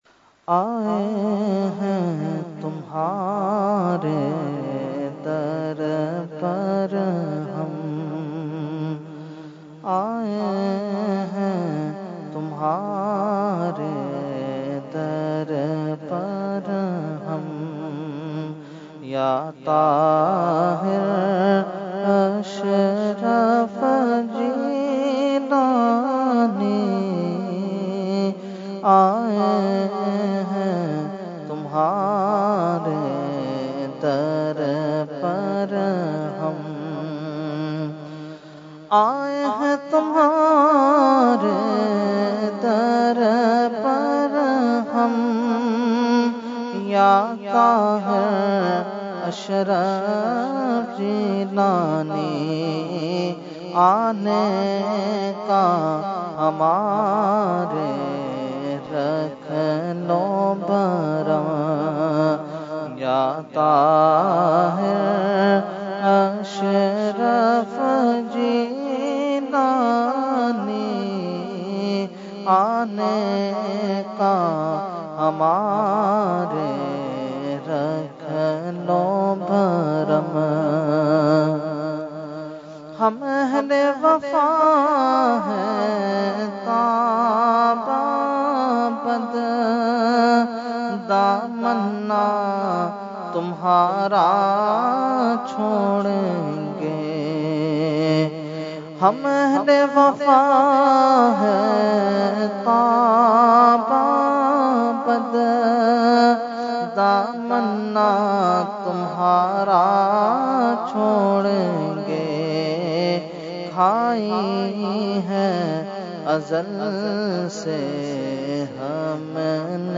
Category : Manqabat | Language : UrduEvent : Urs Qutbe Rabbani 2019